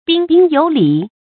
注音：ㄅㄧㄣ ㄅㄧㄣ ㄧㄡˇ ㄌㄧˇ
彬彬有禮的讀法